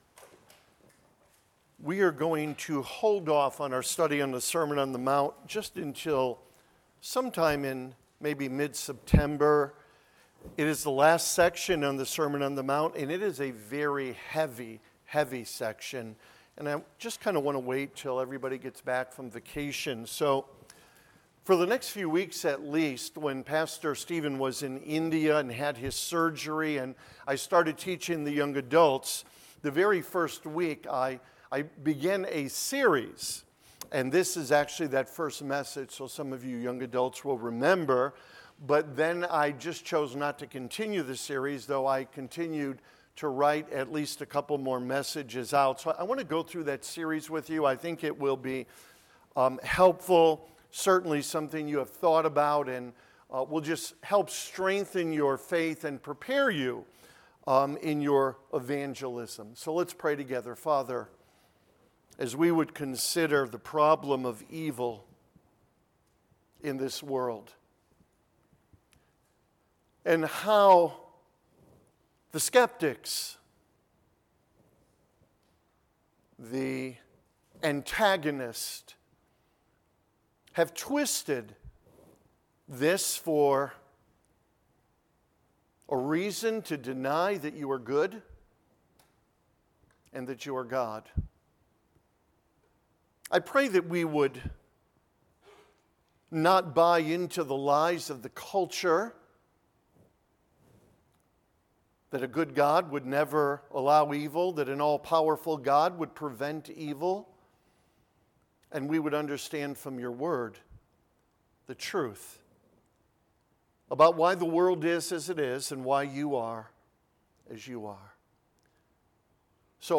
8.24.25-Sermon.mp3